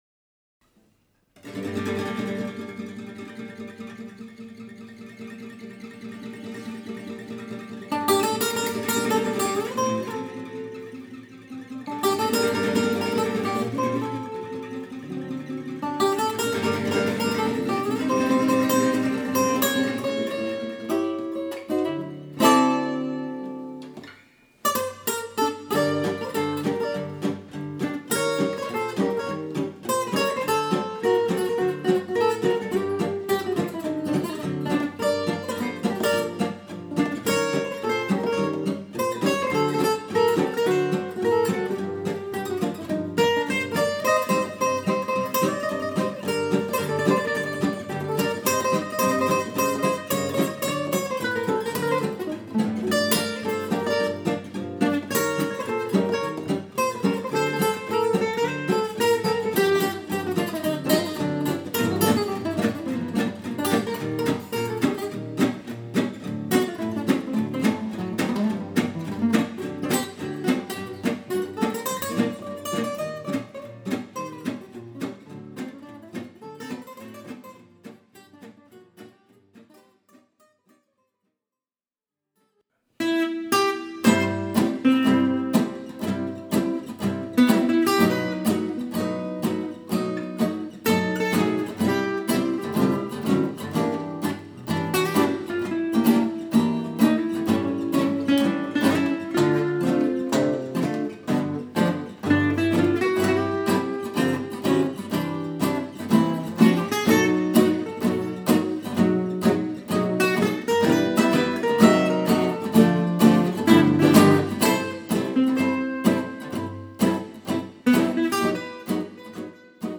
swing manouche